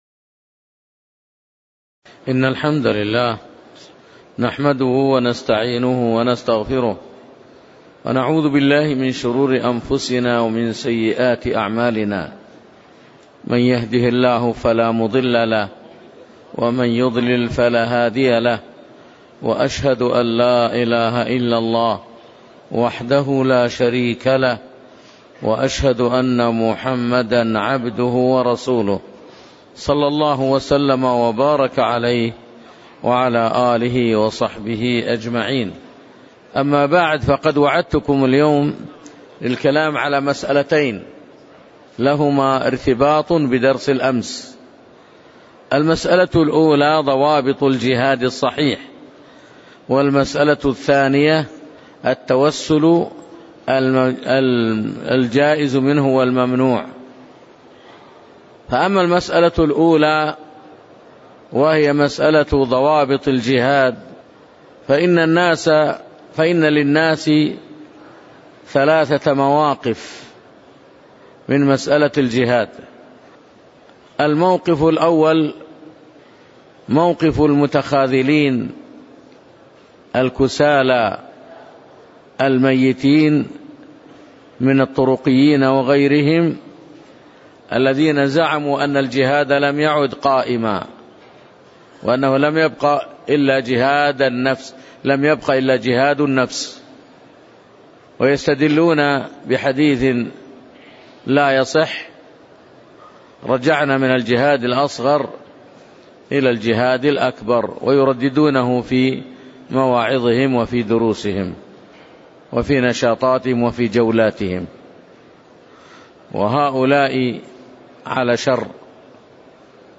تاريخ النشر ١٣ ذو القعدة ١٤٣٨ هـ المكان: المسجد النبوي الشيخ